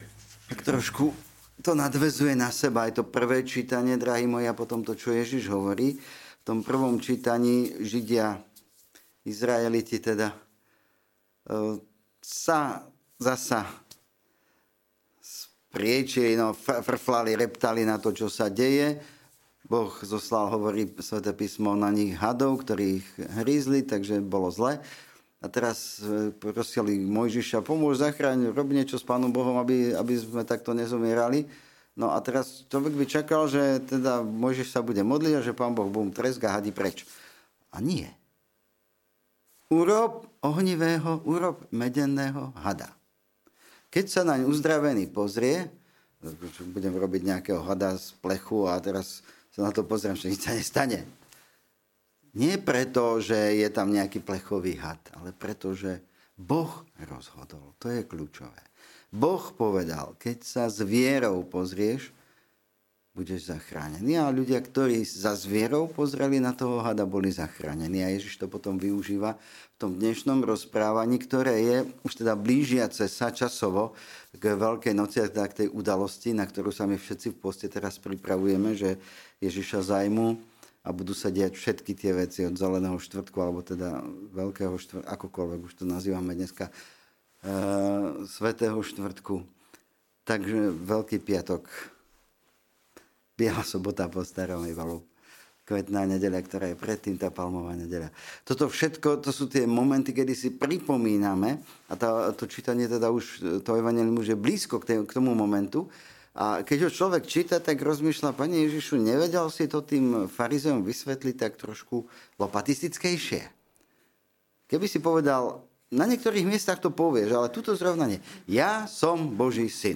Podcasty Kázne PRIJÍMAJME KRISTA TAKÉHO